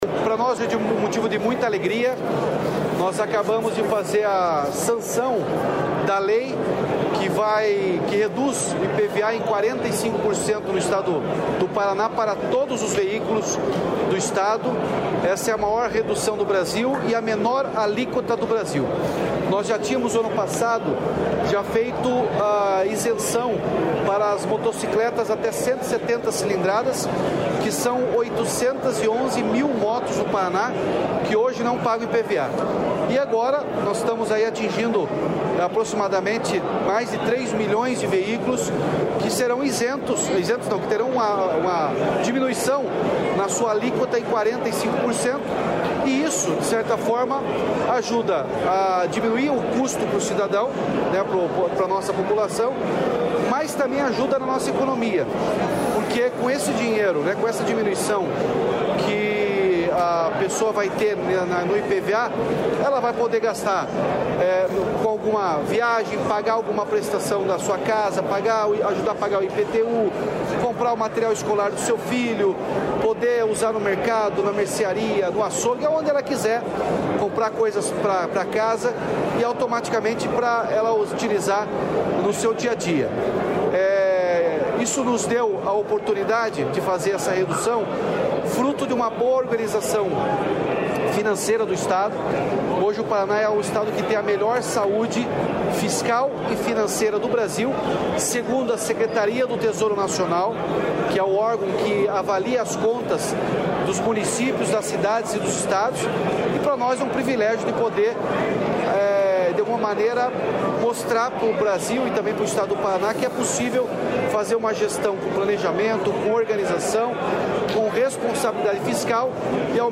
Sonora do governador Ratinho Junior sobre a redução do IPVA